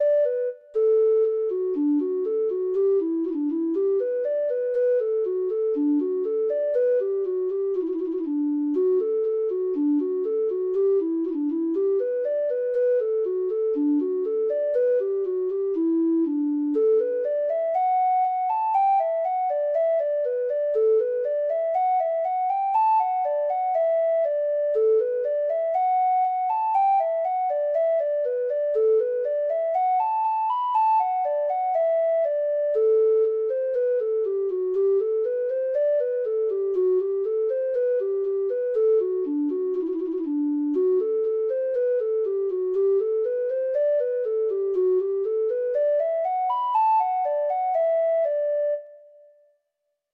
Traditional Music of unknown author.
Treble Clef Instrument Sheet Music